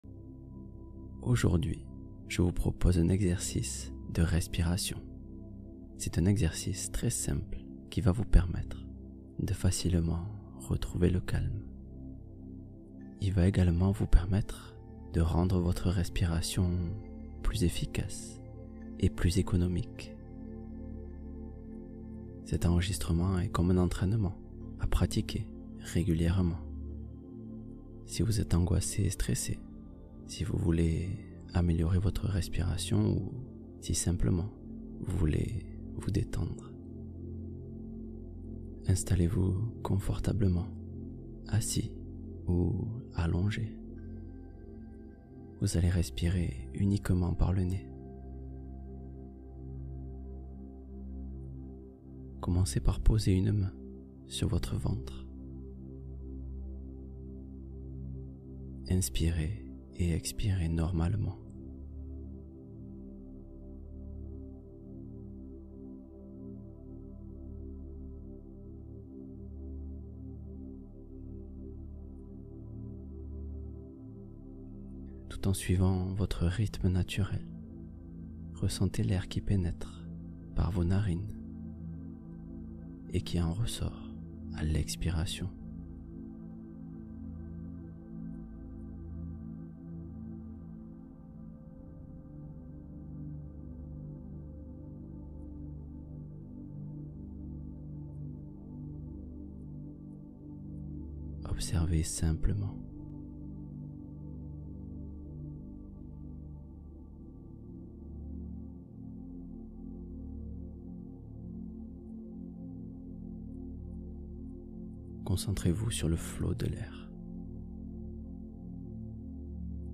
La chaleur de l’hiver : méditation du soir avec affirmations positives